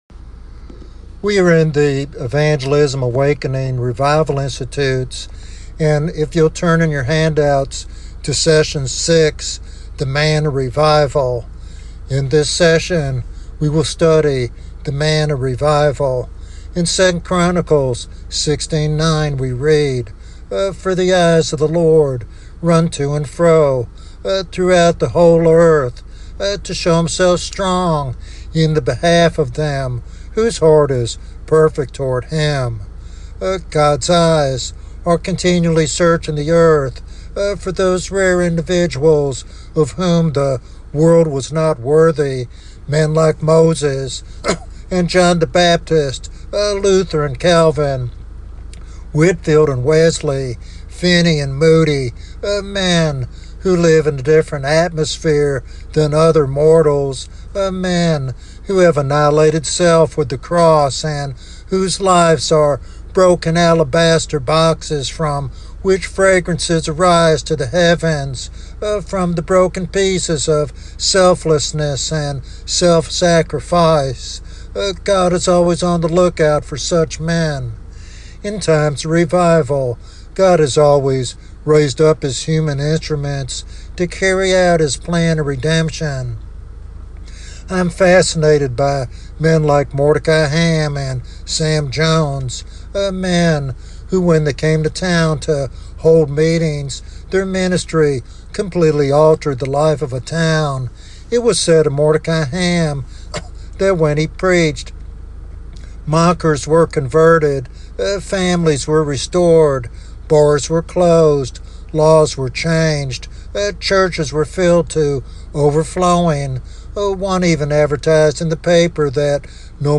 This sermon challenges believers to embrace selflessness and unwavering commitment to Christ to be effective revival agents.